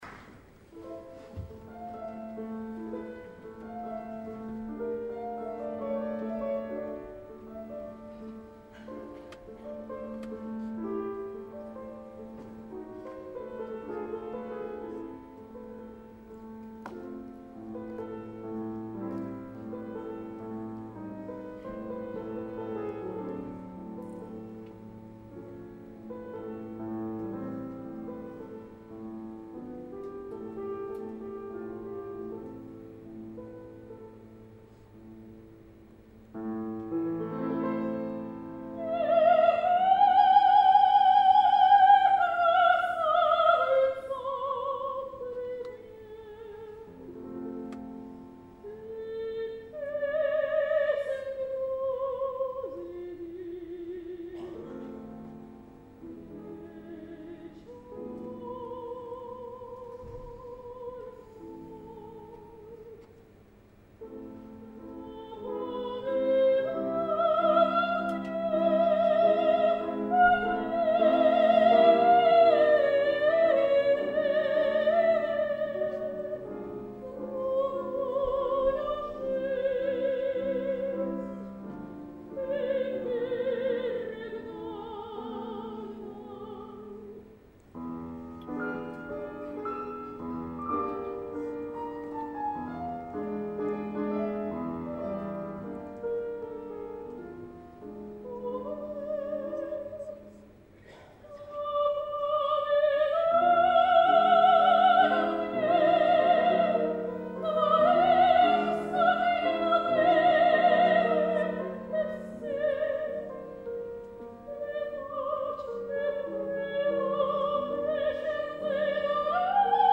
лирико-колоратурное сопрано